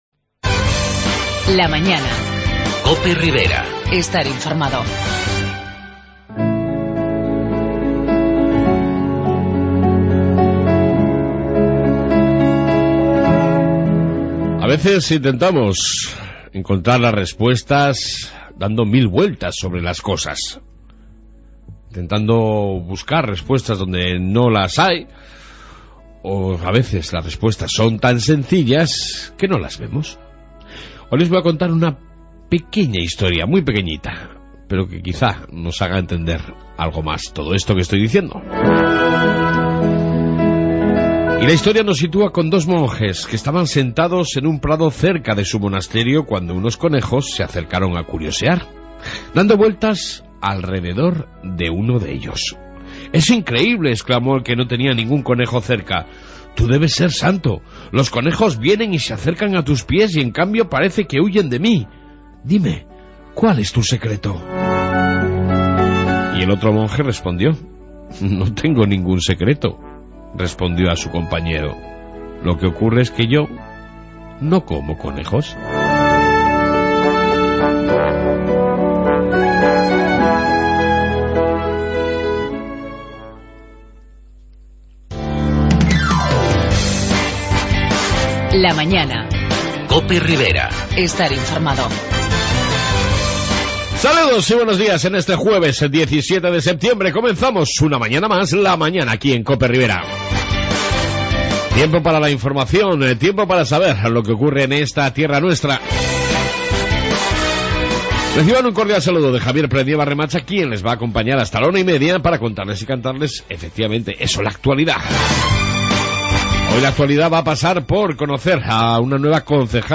AUDIO: Reflexión diaria y Amplia entrevista con la Concejal de Industria,Empleo, Comercio, OMIC, sanidad y turismo Sofia Pardo del ayuntamiento de...